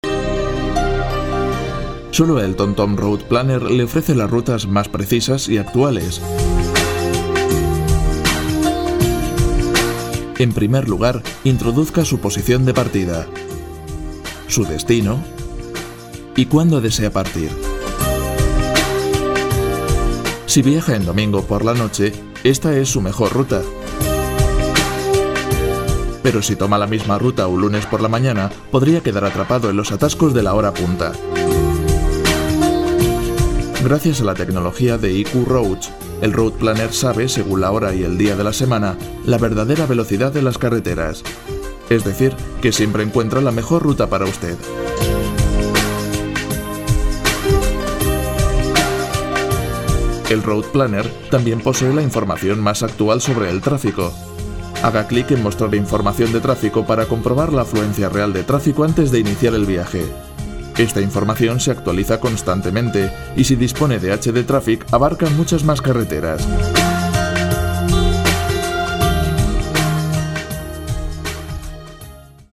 Si está buscando una voz joven, seria, amable y cálida para su proyecto de locución, yo puedo ofrecerle un servicio de calidad, rápido y económico.
Sprechprobe: Industrie (Muttersprache):
Castilian accent (native voice talent from Spain, living in Madrid), ideal if you are looking for an european spanish accent or if your target market is Spain. Warm, deep and sensual for commercial and promos; warm, deep and serious for institutional promos, presentations, etc. Younger voice for other kind of projects.